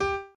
b_pianochord_v100l8o5g.ogg